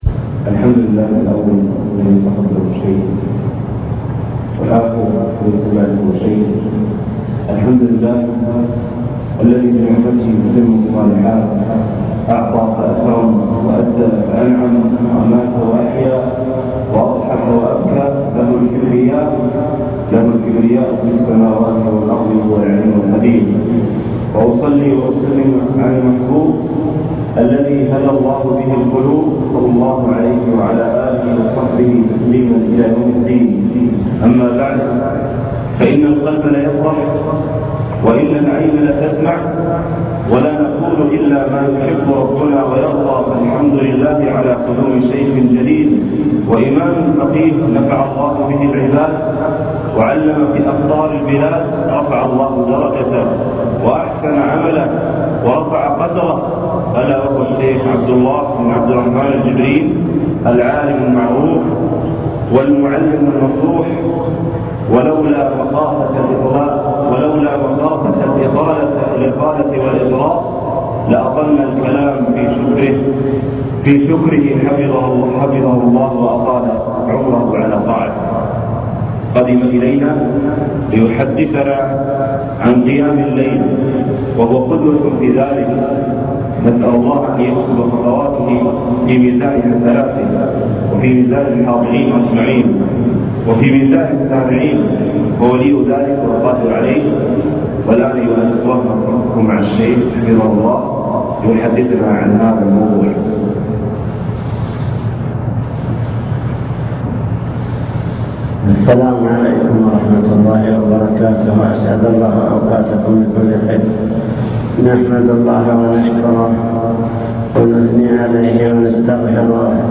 المكتبة الصوتية  تسجيلات - محاضرات ودروس  محاضرة بعنوان قيام الليل